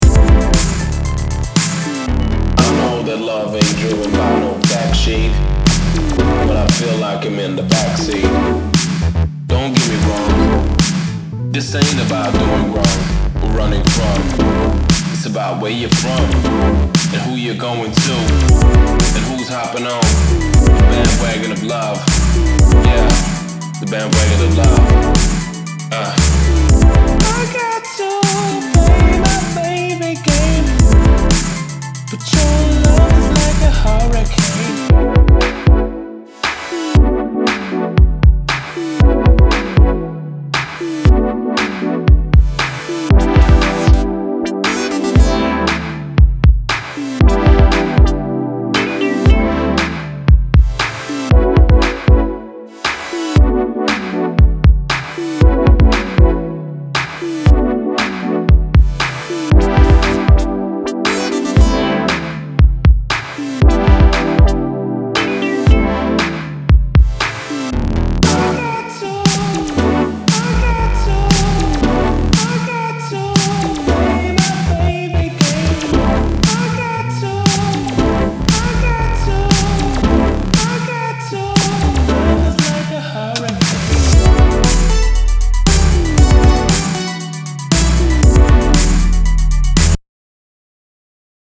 dub and lo-fi music
Disco Electronix Wave Pop